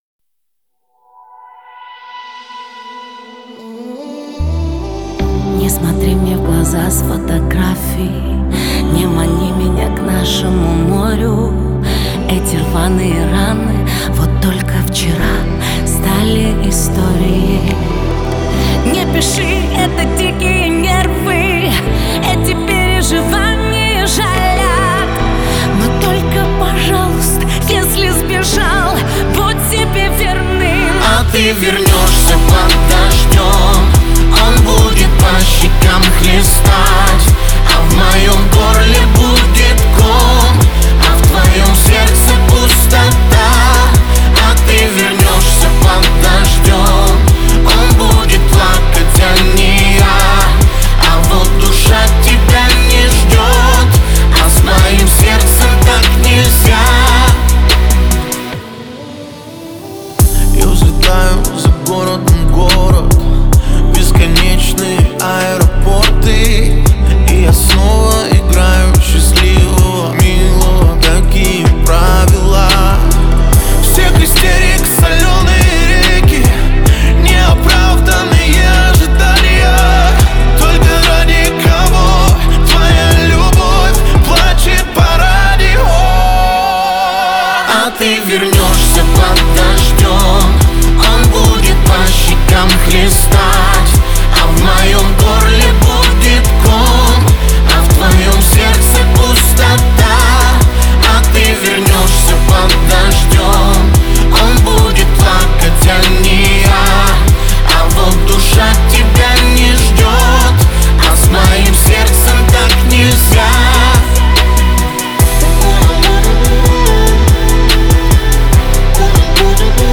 это трогательная поп-баллада